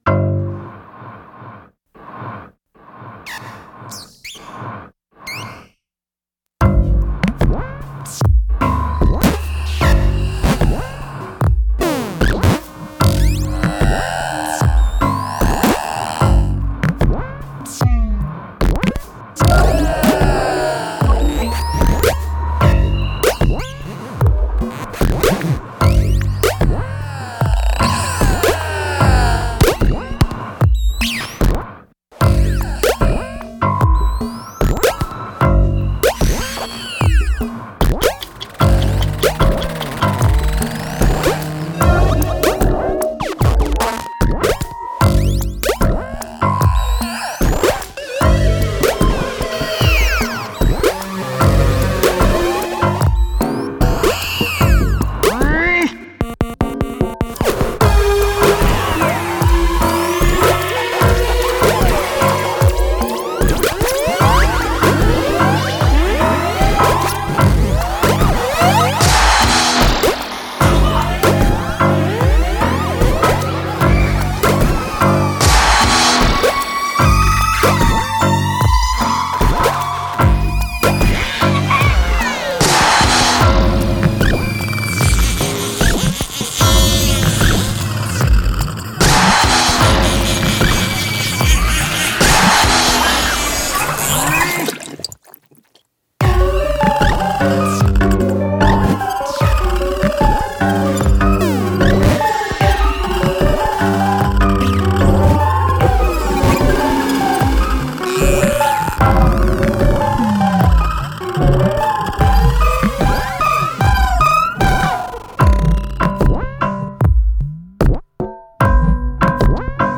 stripped-down
without samples